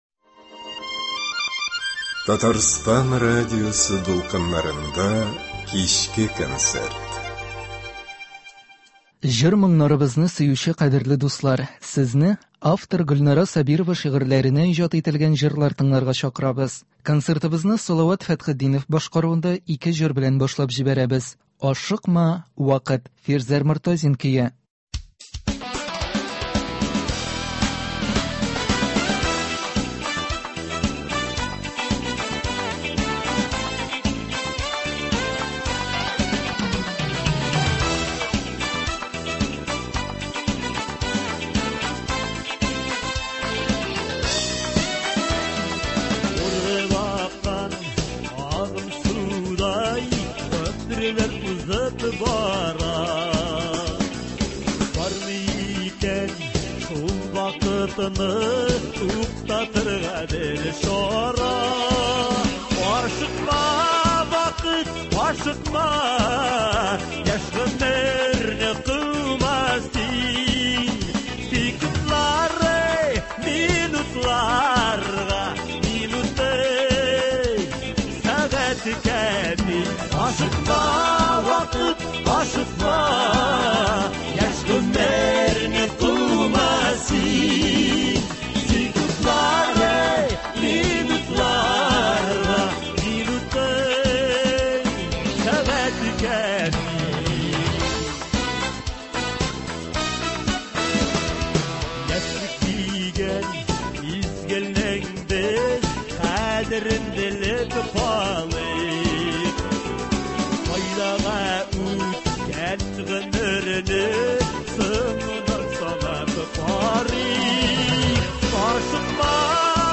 Гөлнара Сабирова шигырьләренә җырлар.
Концерт (16.12.22)